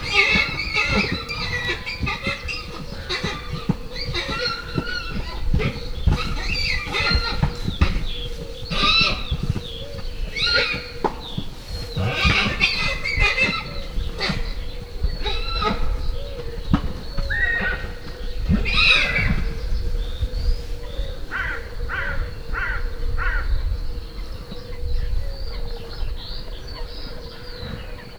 Directory Listing of /_MP3/allathangok/hortobagyinemzetipark2013_premium/vadlo/